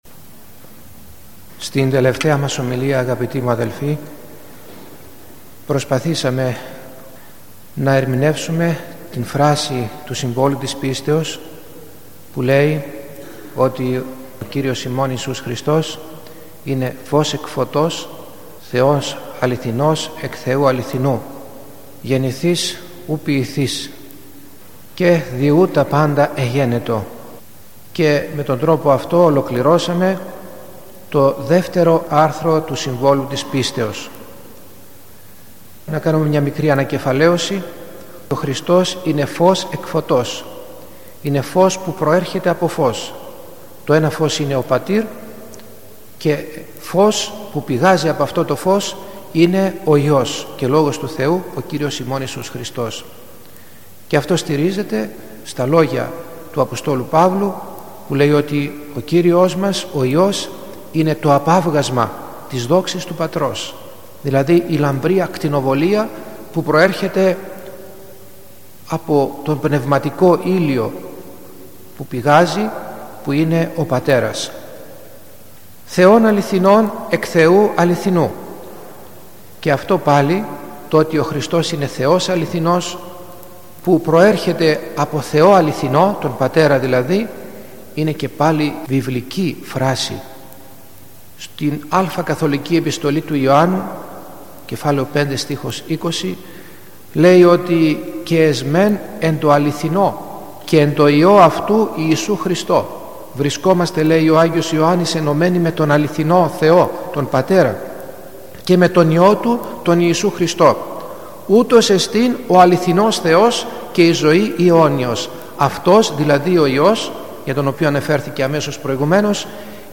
Ομιλία 42η, Ηχητική
Διαστρεβλωμένα χωρία περί του Ιησού Χριστού – Ομιλία στο Σύμβολο της Πίστεως